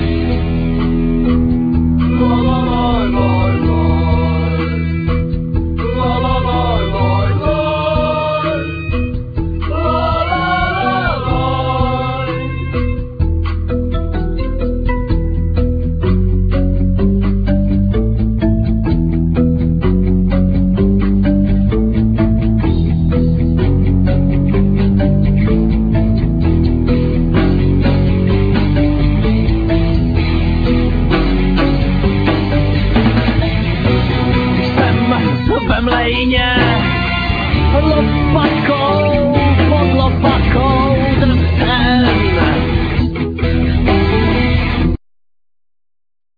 Vocal,Violin,Double bass,Percussion
Guitar,Vocal
Keyborard,Piano,Vocal
Drums,Vocal,Sampler